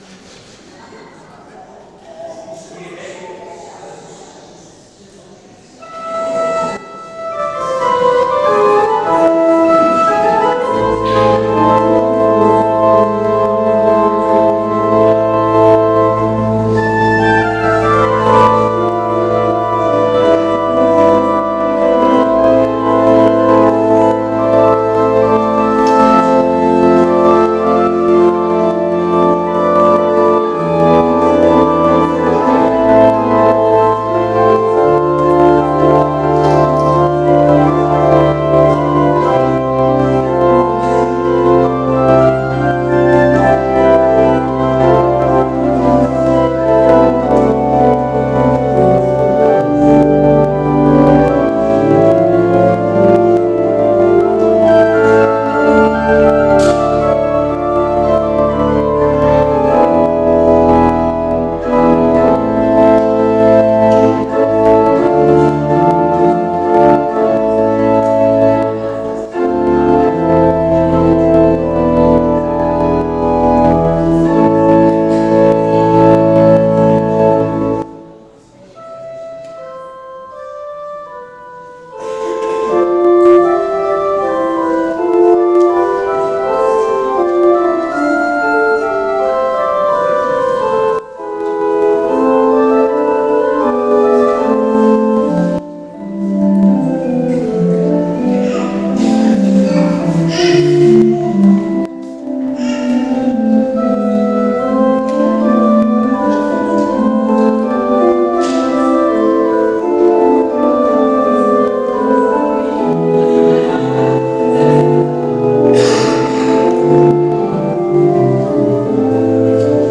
Aktuelle Predigt